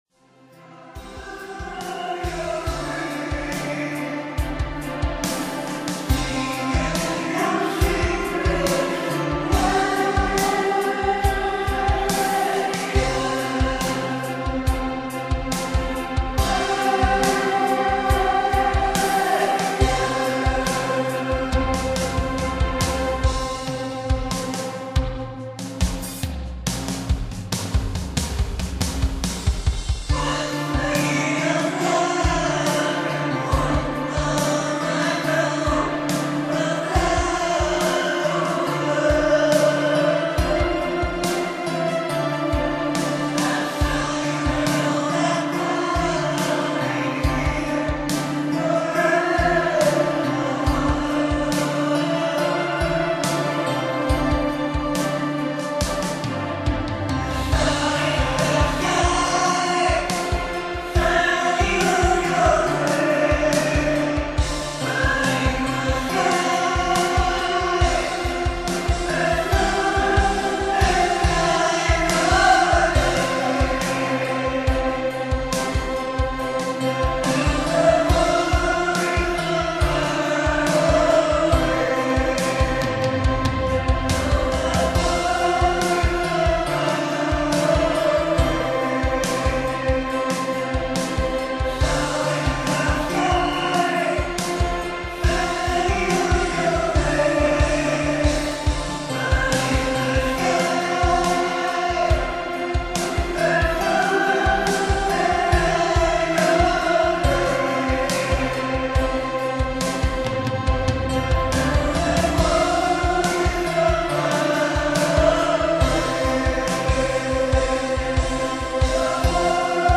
Valentine's Day Party.